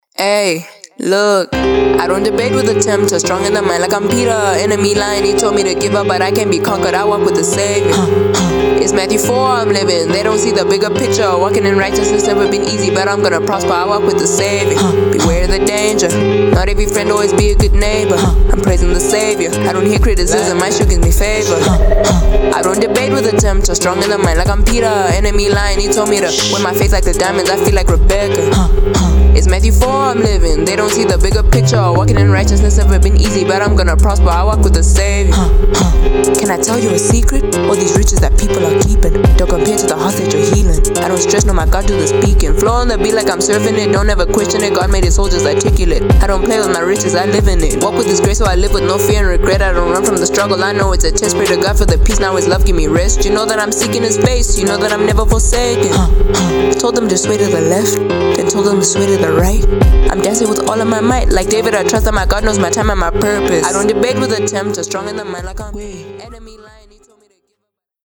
Rap (Recorded on Phone Mic)
BEFORE MIXING AND MASTERING